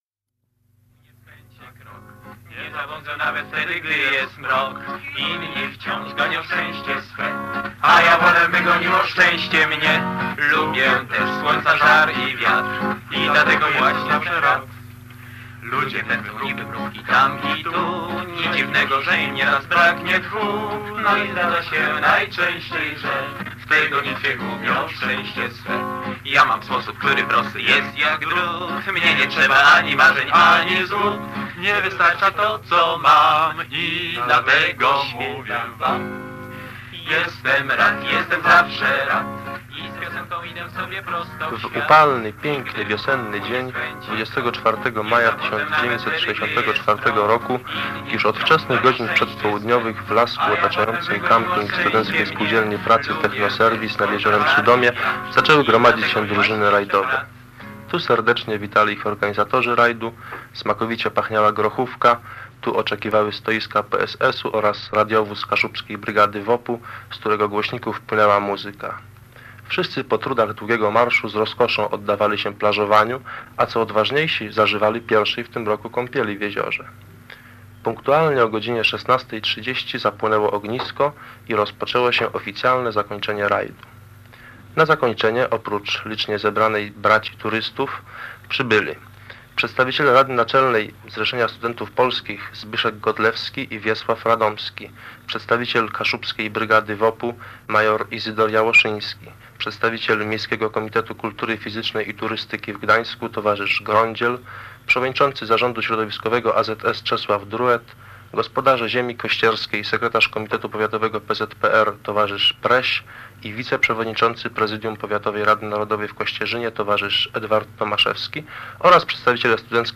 Reportaż z zakończenia rajdu w Sudomiu